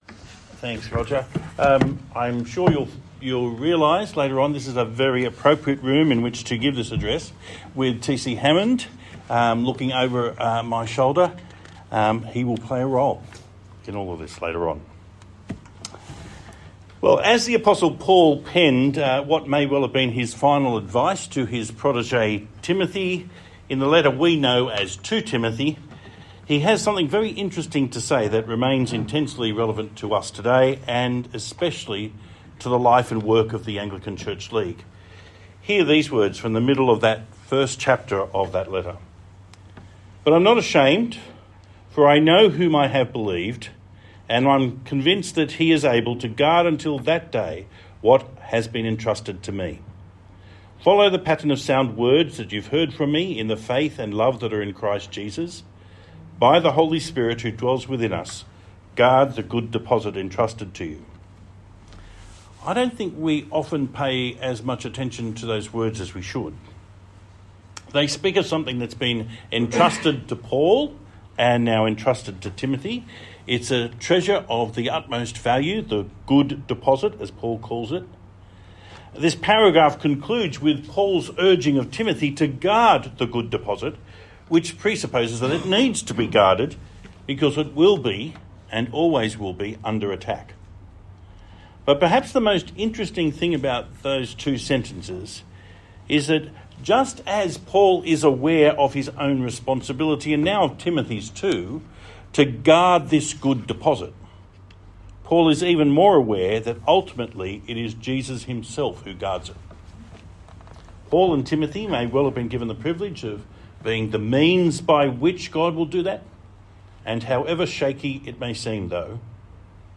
The meeting was held in the T.C. Hammond Common Room – an appropriate venue, as you will see from his address. The talk is more than just a fascinating glimpse into the history of the Diocese of Sydney.